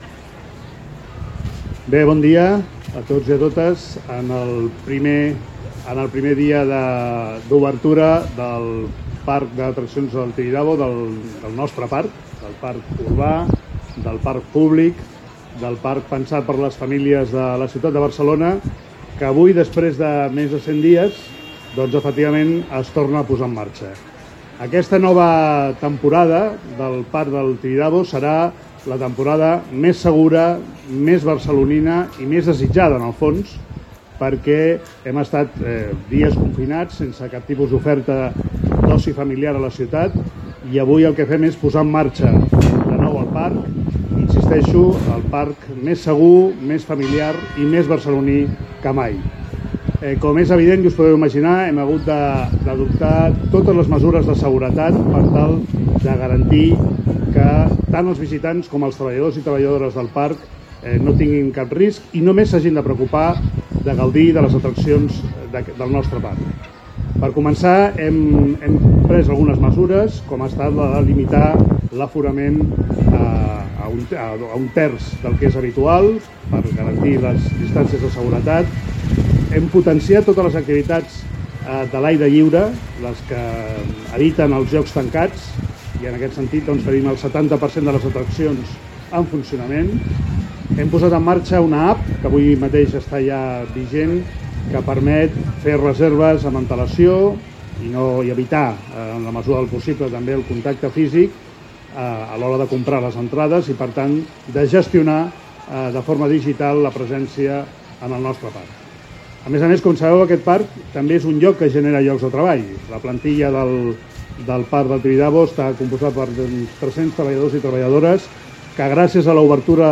El primer tinent d’alcaldia i president de BSM, Jaume Collboni i el regidor del districte de Sarrià-Sant Gervasi i president de PATSA, Albert Batlle, han participat aquest matí a la reobertura del Tibidabo.
Material relacionat Descarregar nota de premsa en format PDF Declaracions de Jaumes Collboni i Albert Batlle (Àudio, m4a, 5,2 Mb) Paraules clau tibidabo/